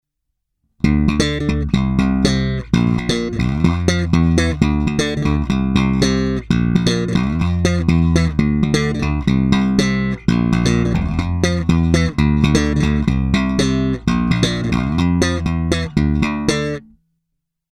Není-li uvedeno jinak, následující nahrávky jsou vyvedeny rovnou do zvukové karty a vždy s plně otevřenou tónovou clonou a s korekcemi v nulové poloze, následně jsou jen normalizovány, jinak ponechány bez úprav.
Slap na oba snímače